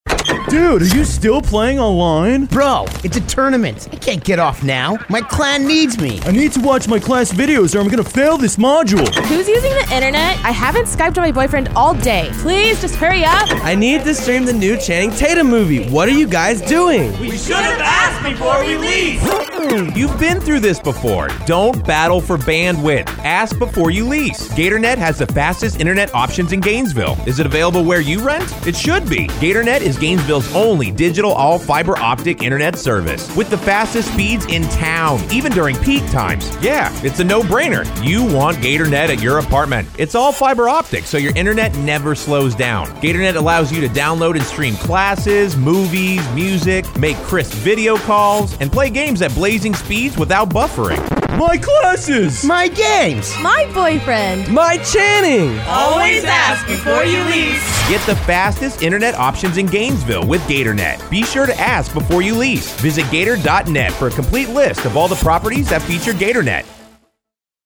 Length Radio Spot